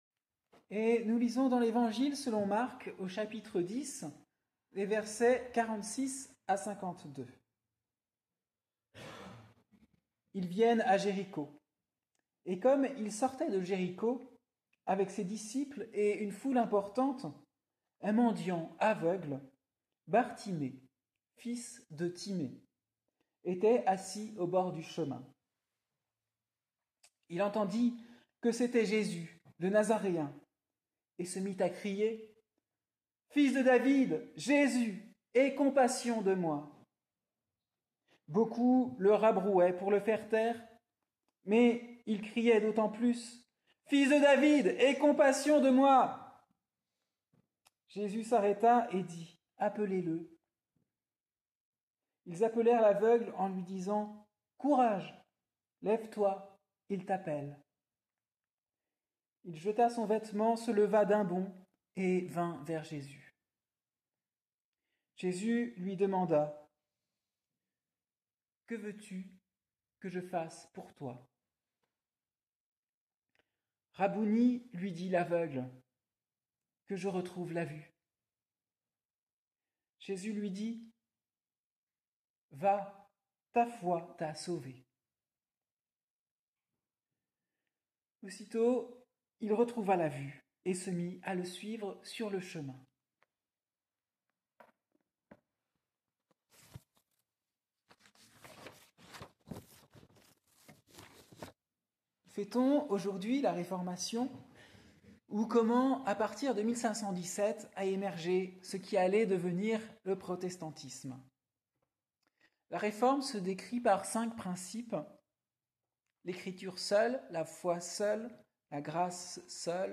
Prédication du dimanche 27 octobre 2024 ; fête de la Réformation